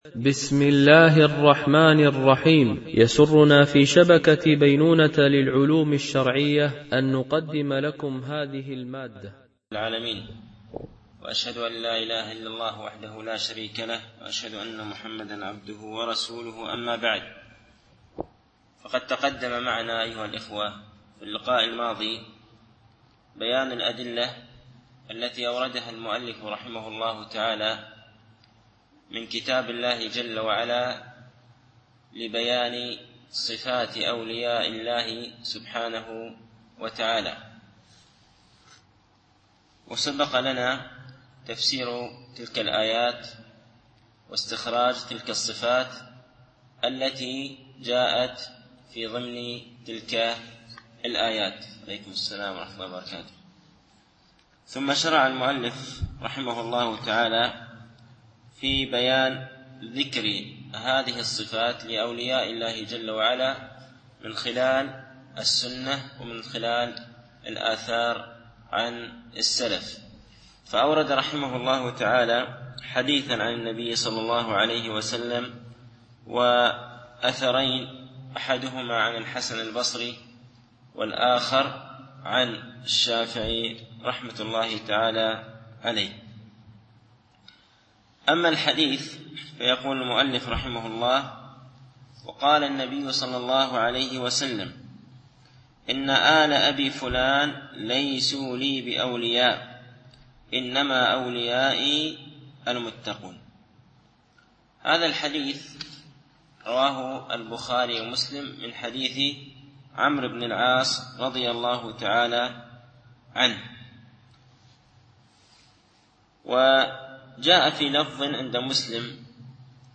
شرح أعلام السنة المنشورة ـ الدرس 179( تكملة سؤال - من هم أولياء الله ؟)